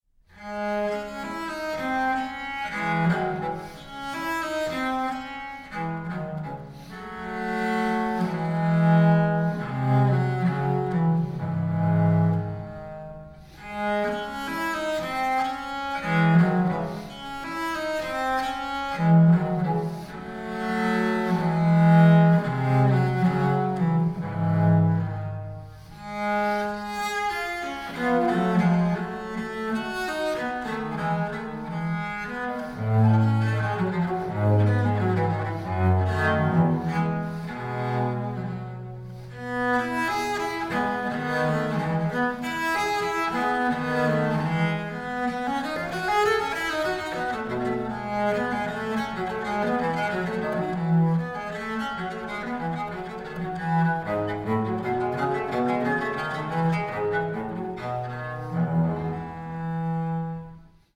for unaccompanied bass viol